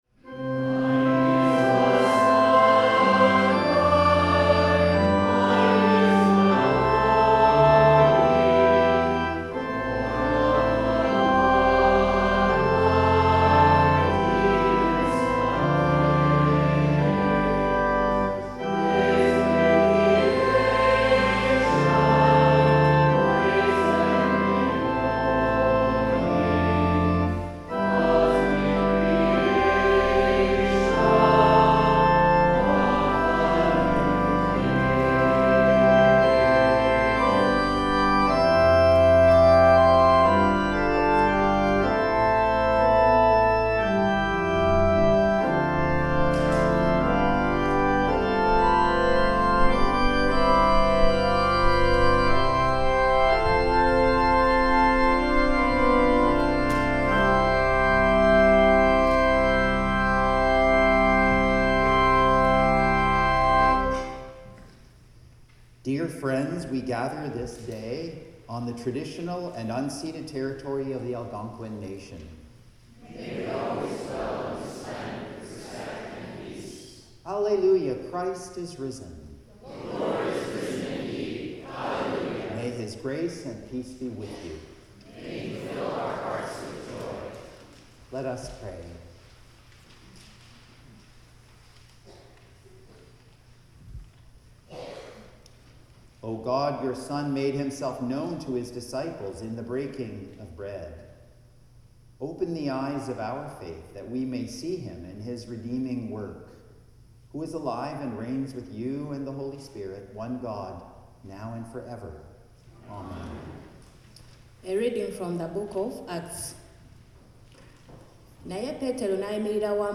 Hymn 3
The Lord’s Prayer (sung)